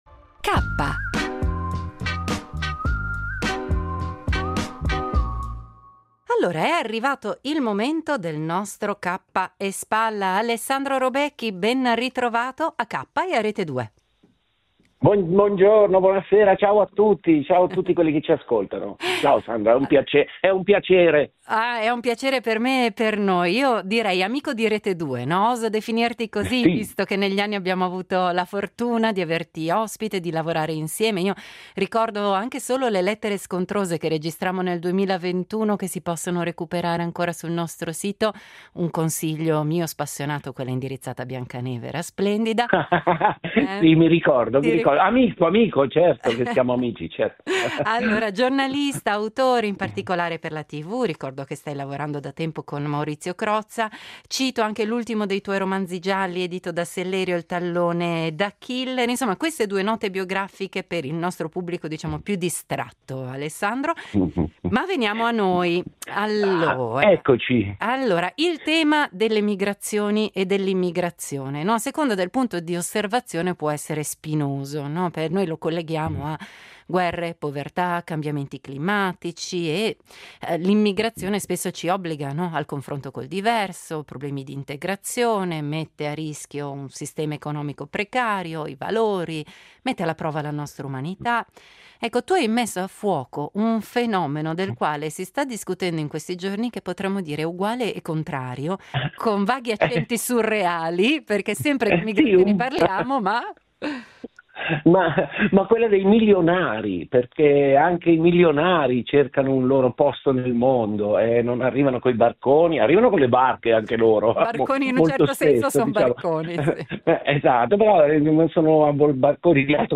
L’editoriale del giorno firmato da Alessandro Robecchi